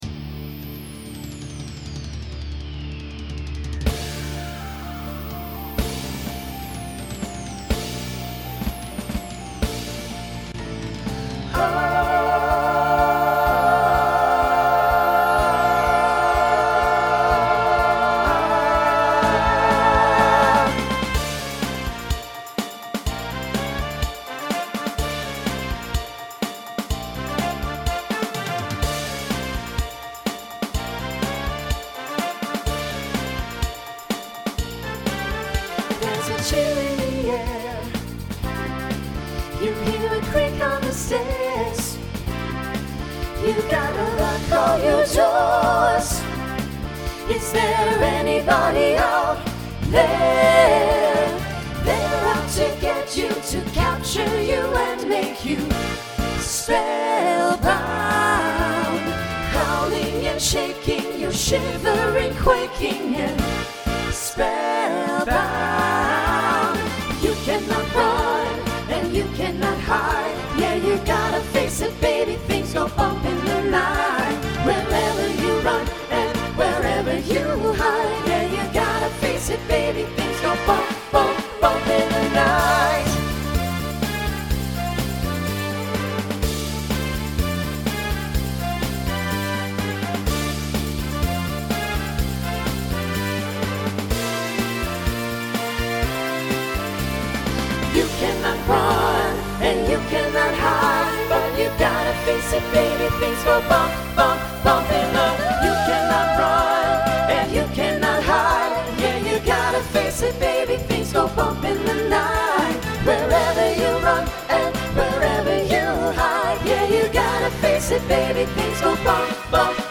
New SSA voicing for 2020